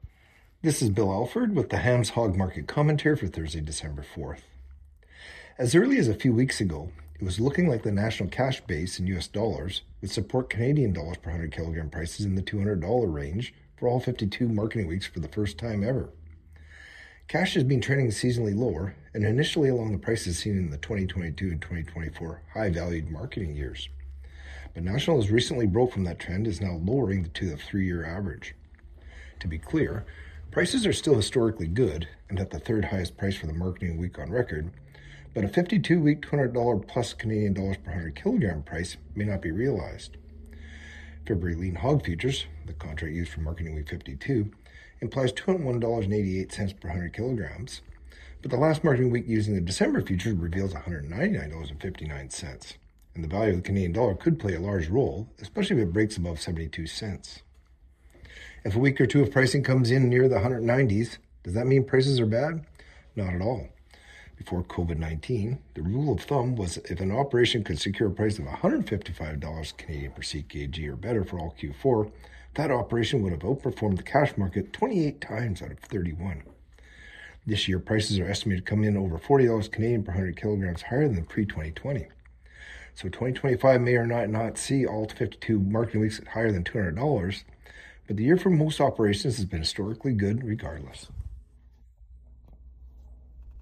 Hog-Market-Commentary-Dec.-4-25.mp3